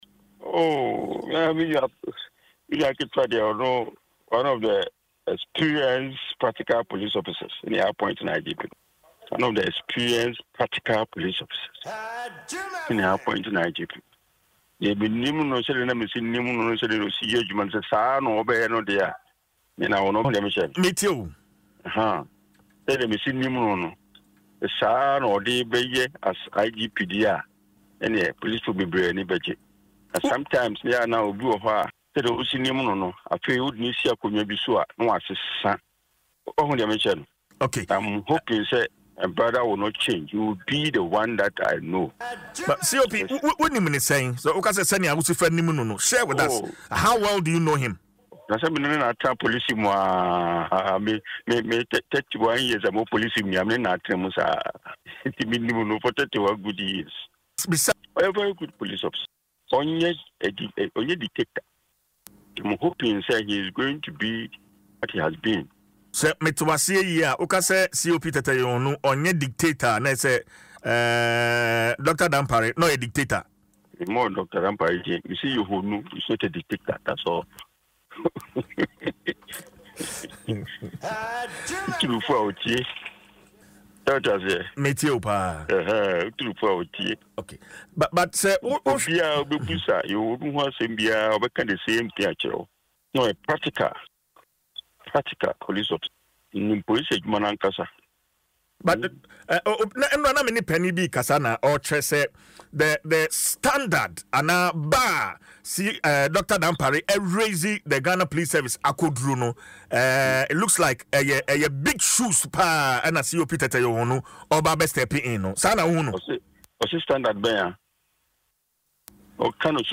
Speaking on Adom FM’s morning show Dwaso Nsem, COP rtd. Mensah said he has worked with COP Yohuno for 31 years and believes he will bring positive change to the Ghana Police Service.